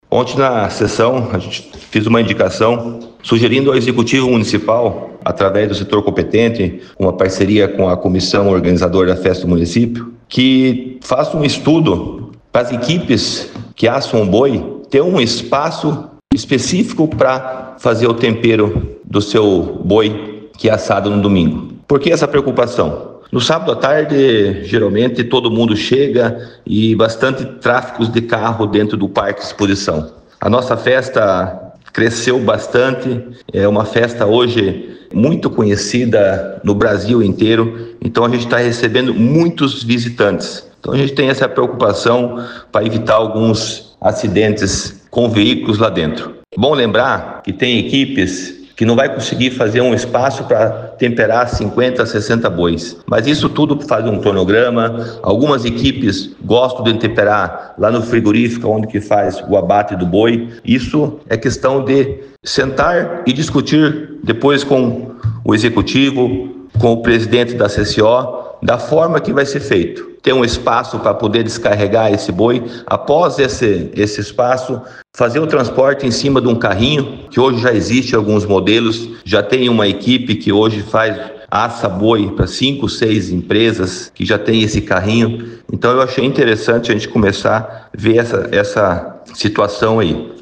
Ouça entrevista do vereador Verde……